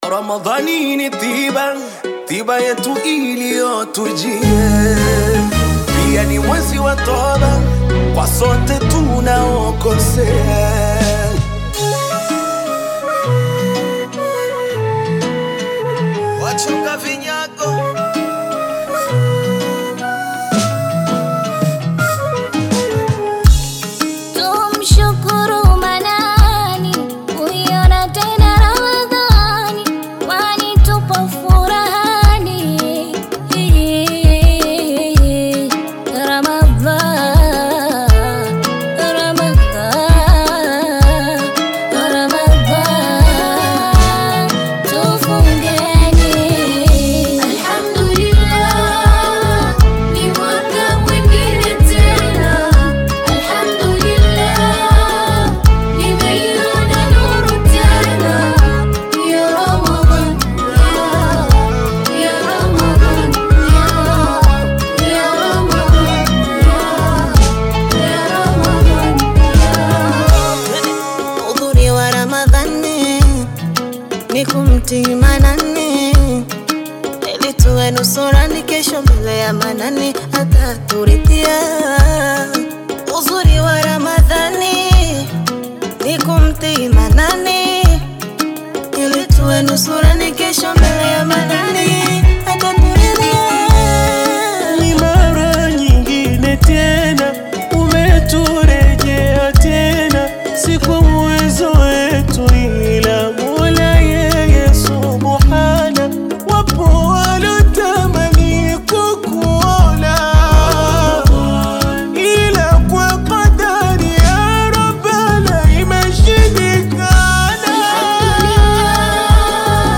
Qaswida music track